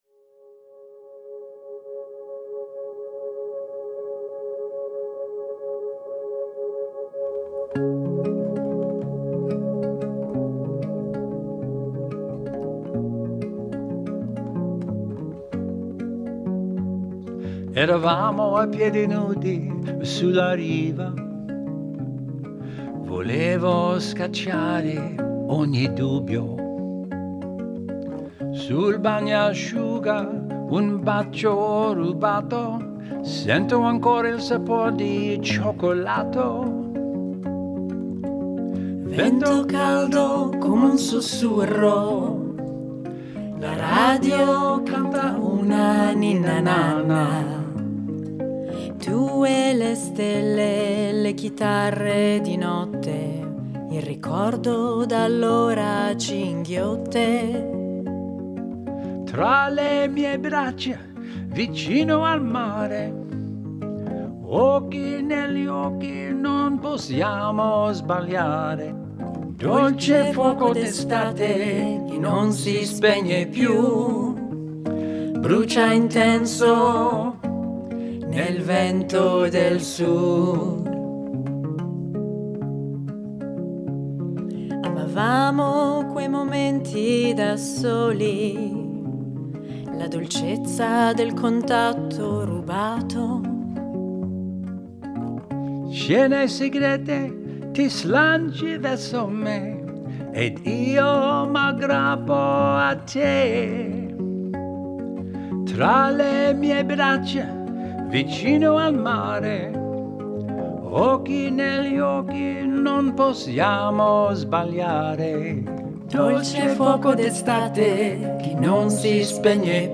bring modern folk, acoustic blues